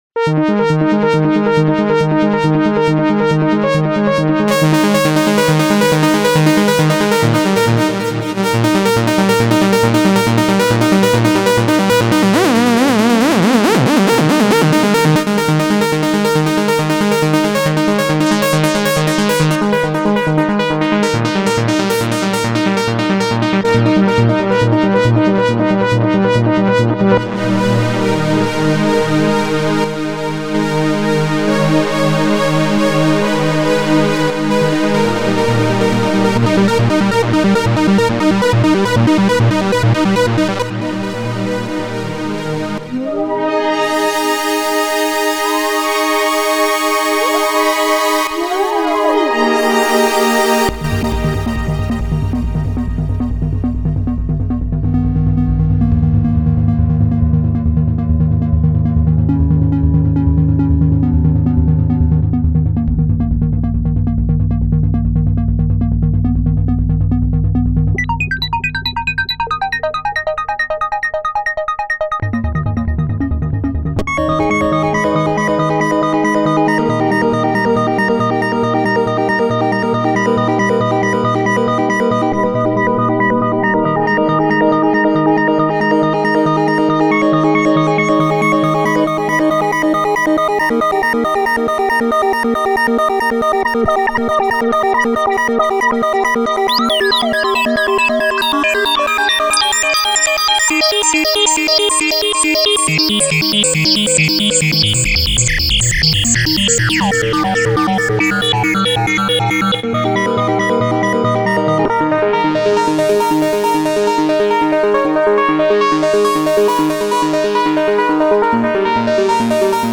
Маленькая лемка Вложения Behringer Pro-800 Analog.mp3 Behringer Pro-800 Analog.mp3 12,6 MB · Просмотры: 2.626